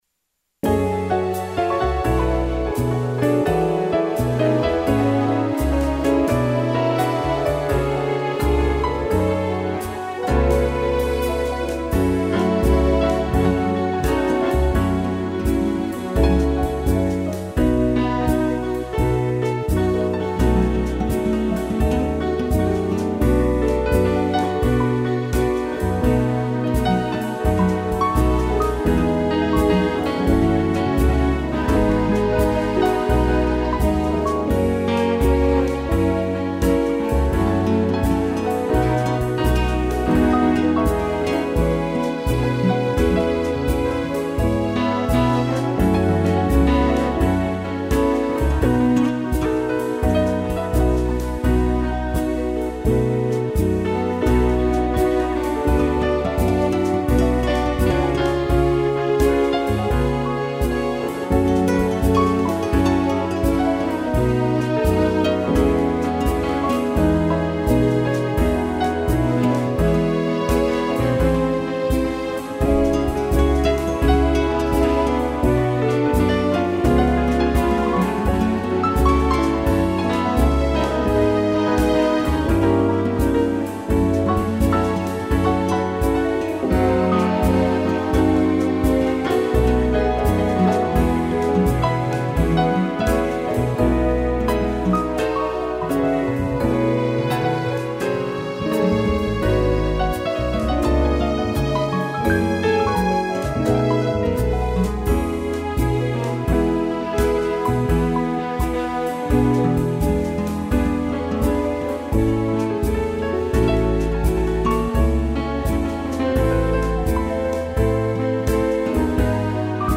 violão
piano, sax e tutti
instrumental